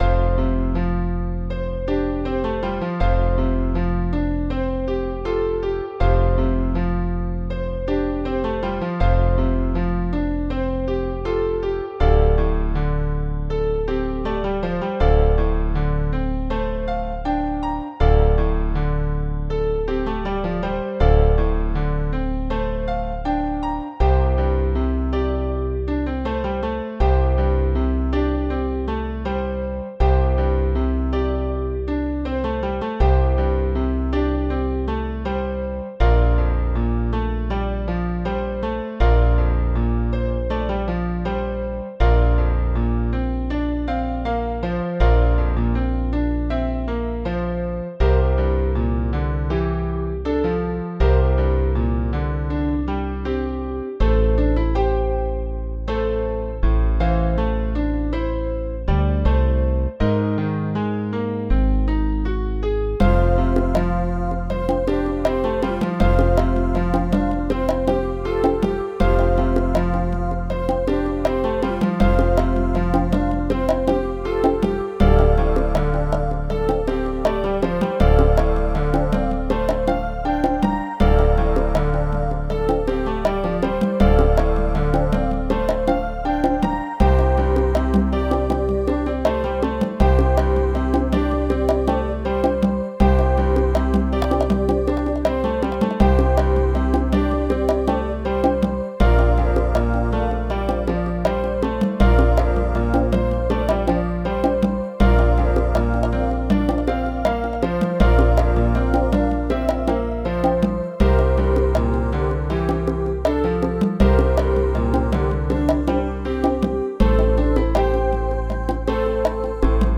Je me suis installé au piano et j’ai commencé à jouer quelques notes.